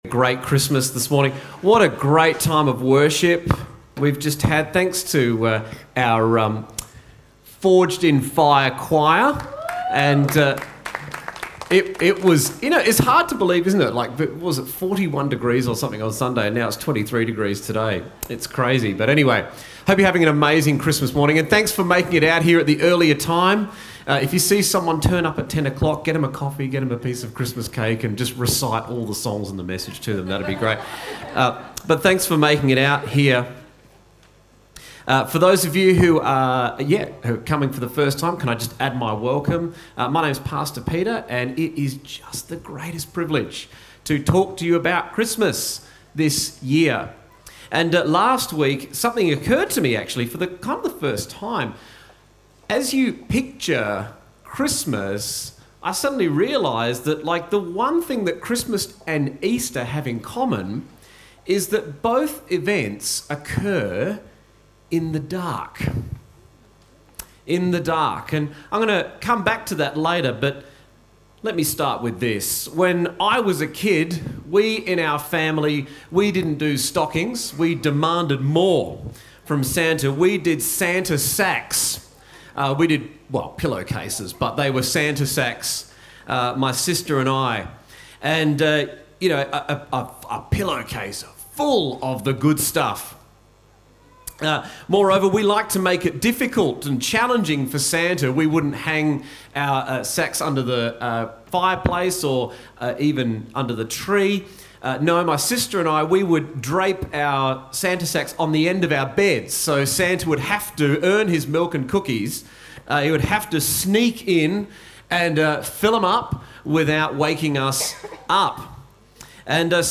A message from the series "God Came Near."